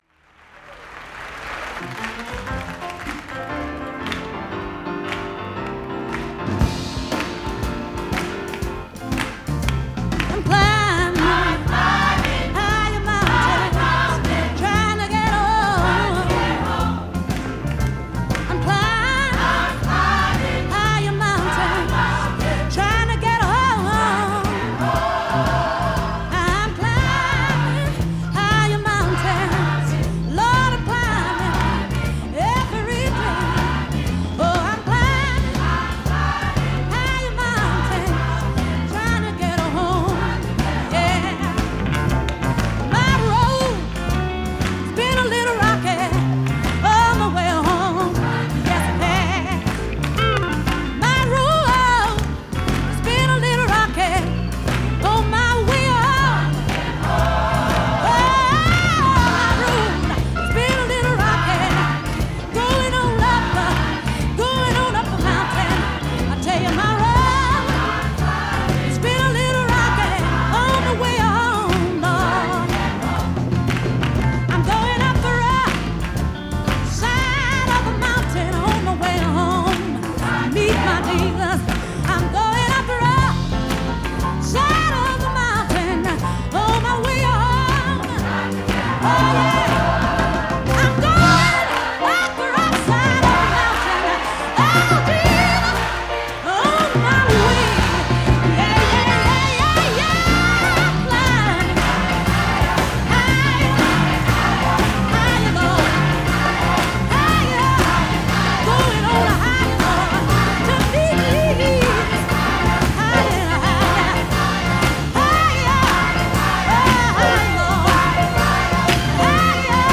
Госпел от серията "велики"